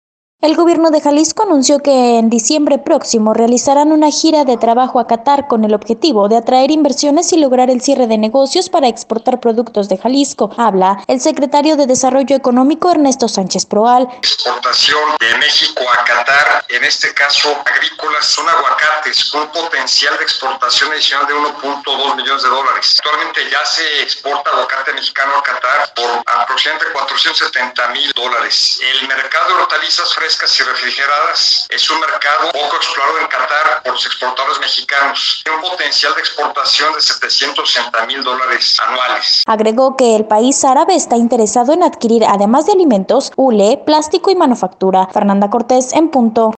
El Gobierno de Jalisco anunció que en diciembre próximo, realizarán una gira de trabajo a Qatar, con el objetivo de atraer inversiones, y lograr el cierre de negocios para exportar productos de Jalisco. Habla el secretario de Desarrollo Económico, Ernesto Sánchez Proal: